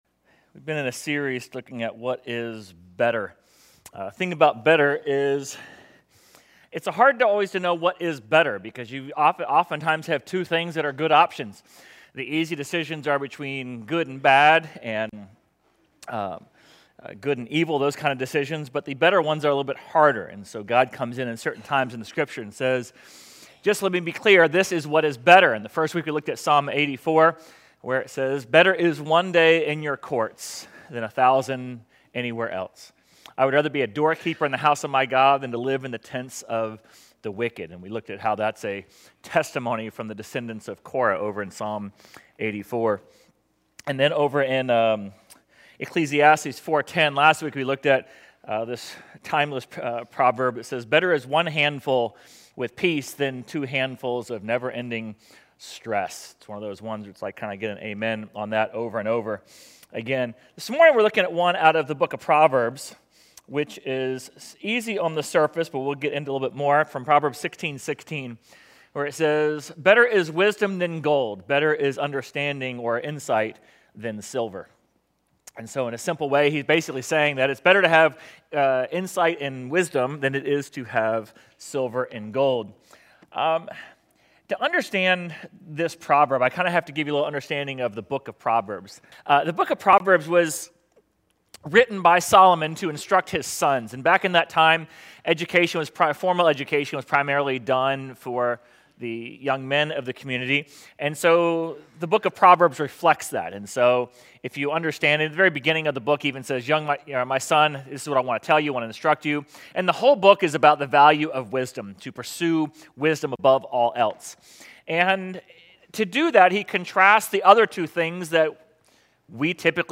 Sermon_9.7.25_1.mp3